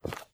STEPS Dirt, Walk 05.wav